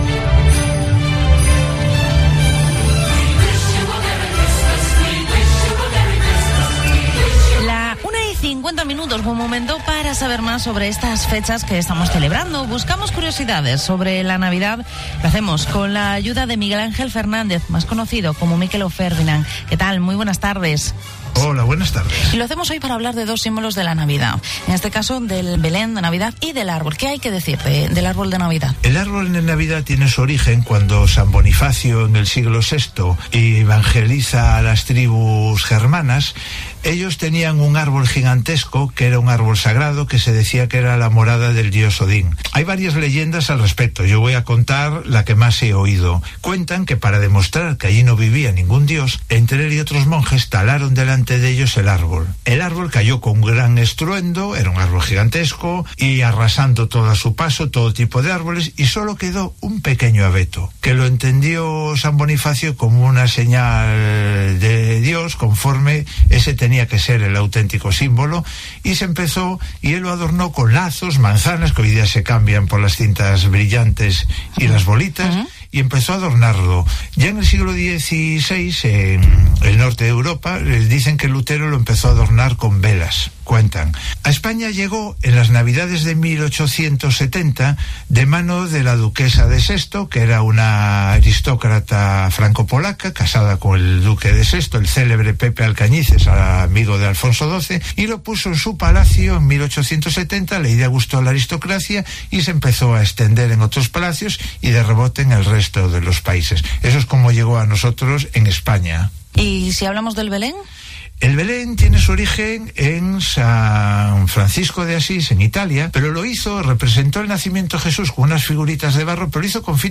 Vigo Entrevista ¿Cuál es el origen del Belén?